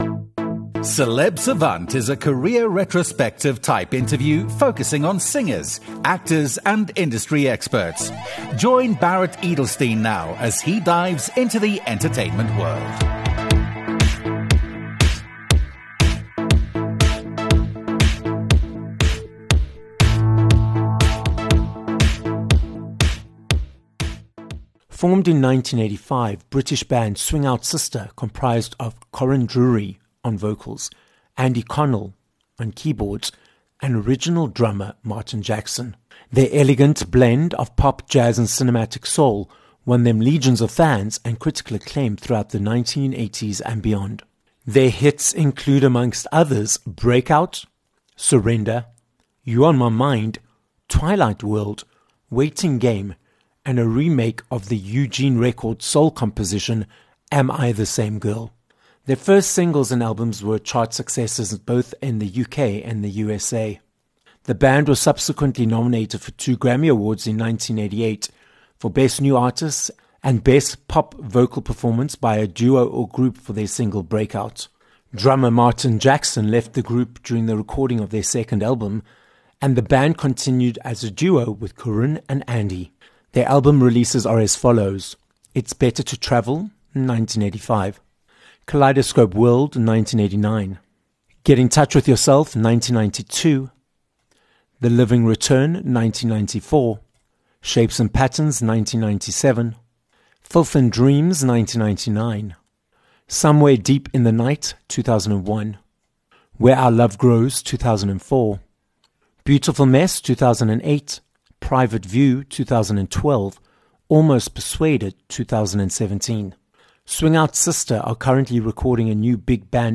Swing Out Sister - a British Grammy-nominated, singer, and songwriter duo - joins us on this episode of Celeb Savant. Corinne Drewery and Andy Connell explain how, in the beginning, John Barry was the band's common ground, how the band's name was chosen and if they still hate it, how they were surprised by their initial success, and more about their latest 8 CD box set, Certain Shades of Limelight, released on Cherry Records.